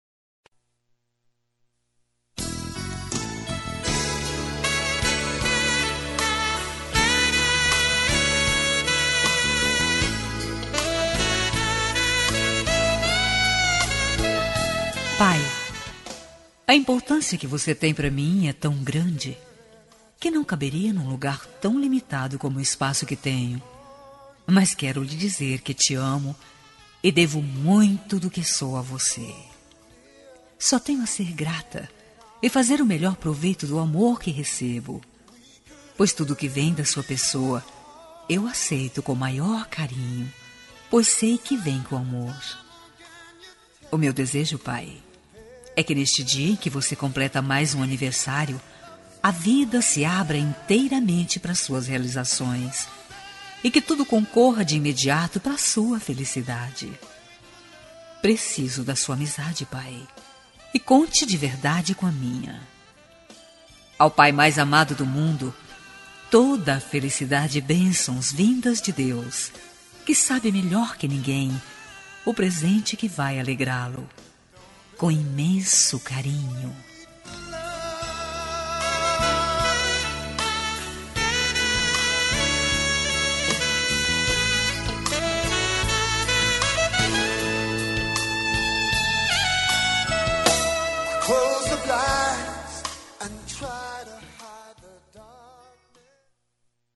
Aniversário de Pai -Voz Feminina – Cód: 11635